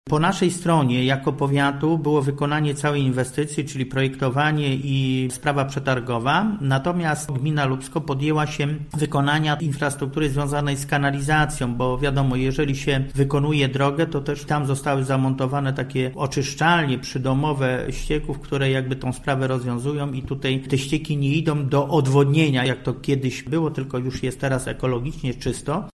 ’- Na przejściach dla pieszych zainstalowano oświetlenia solarne – opowiada Józef Radzion, starosta żarski.